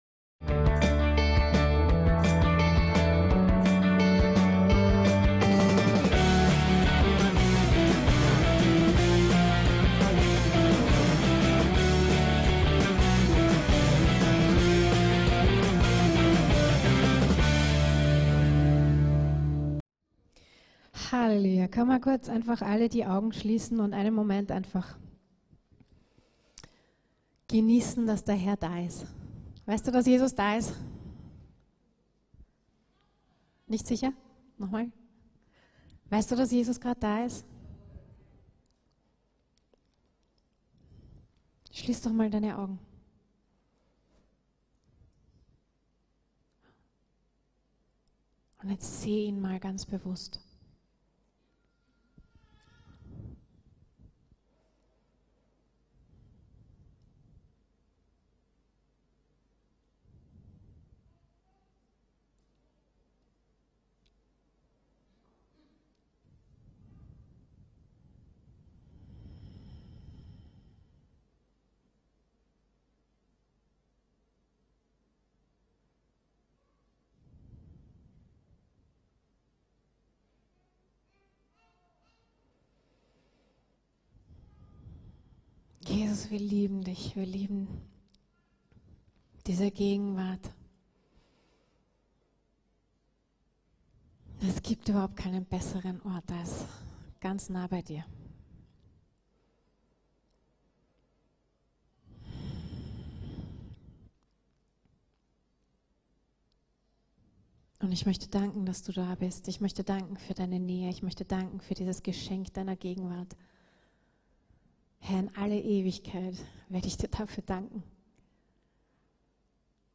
WIR SIND DER TEMPEL ~ VCC JesusZentrum Gottesdienste (audio) Podcast